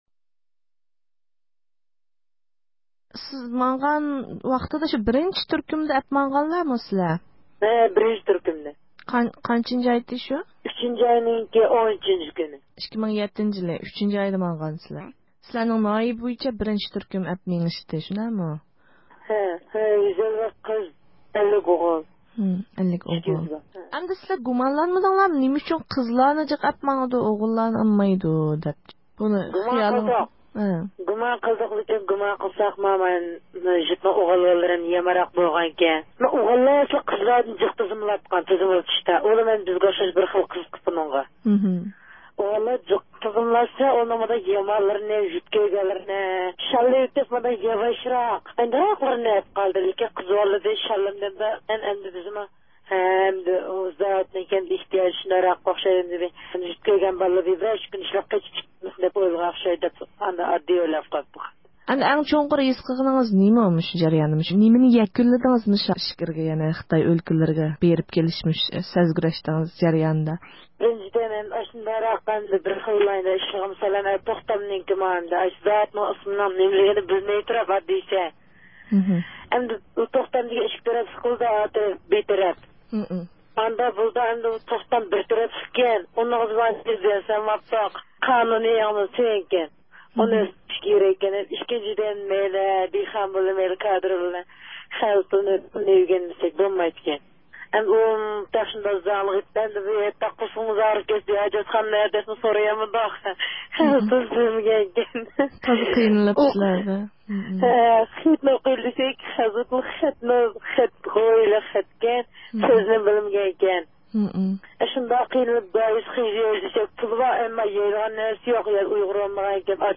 سۆھبىتىنىڭ